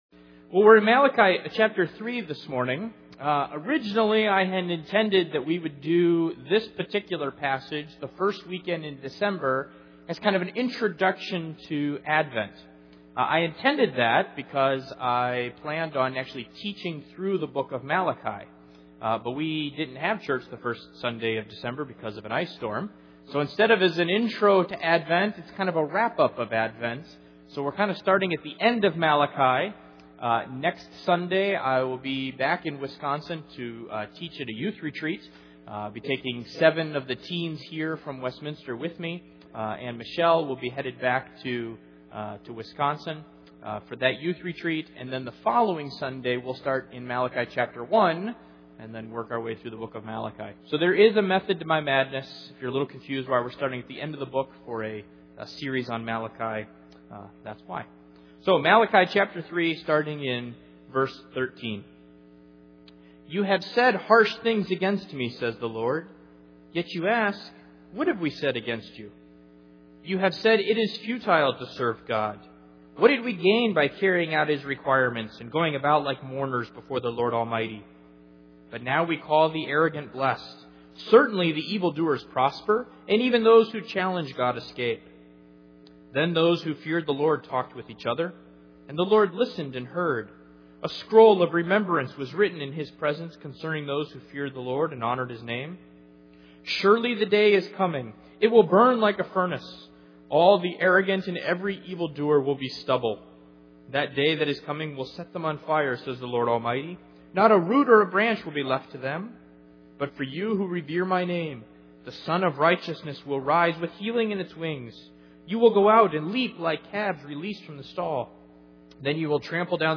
Malachi 3:13-4:5 Service Type: Sunday Morning The people in Israel grew tired of waiting for the Advent of the Messiah.